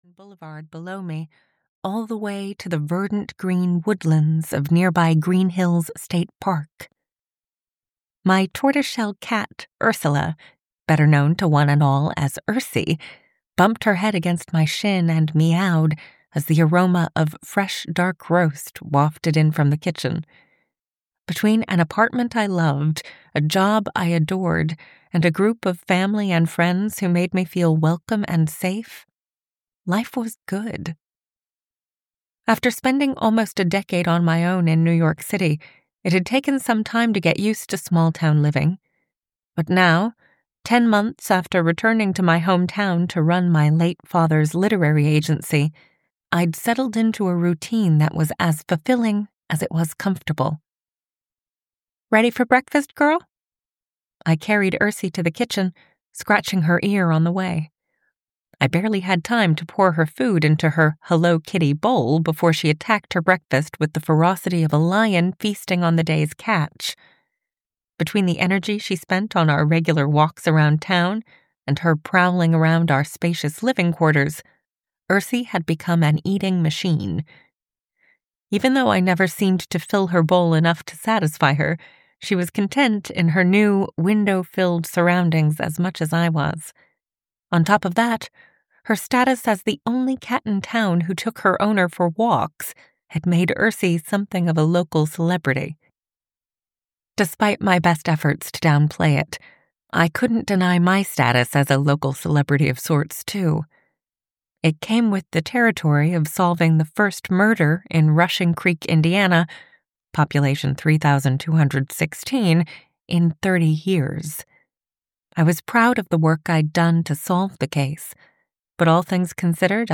A Genuine Fix (EN) audiokniha
Ukázka z knihy